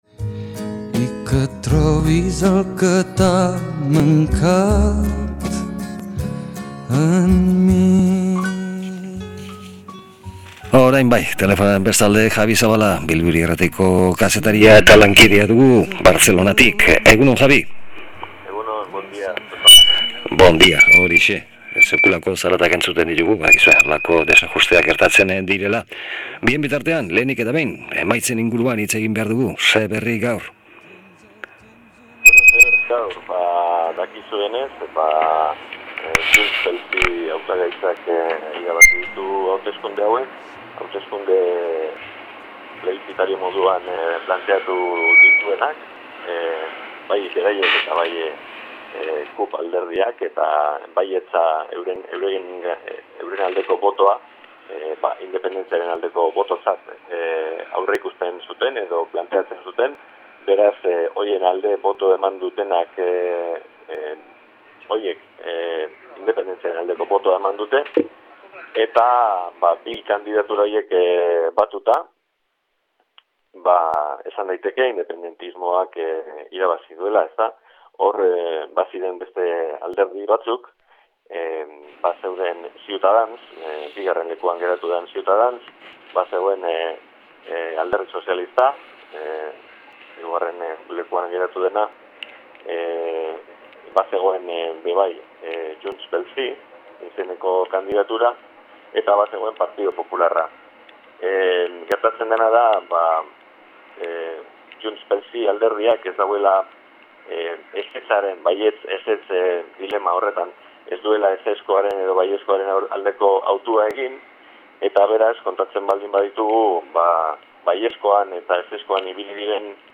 Kataluniako hauteskundeen biharamunean, kronika Bartzelonatik